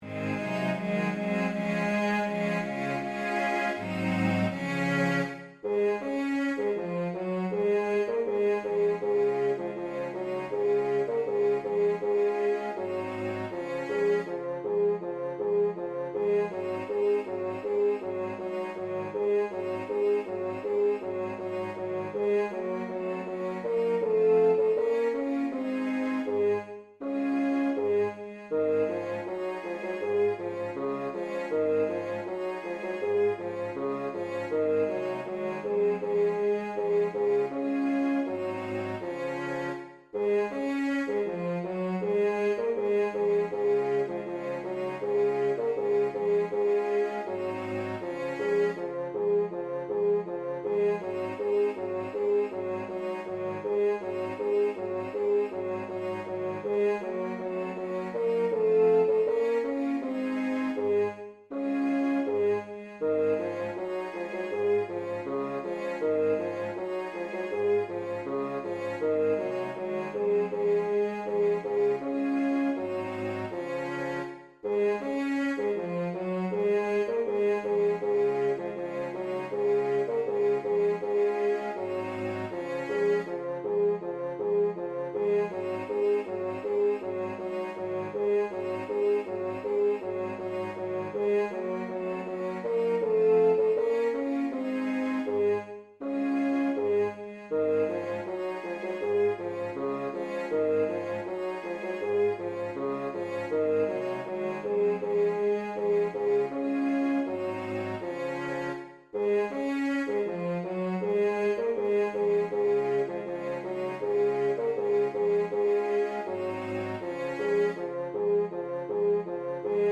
Das Wandern ist des Müllers Lust Bass 1 als Mp3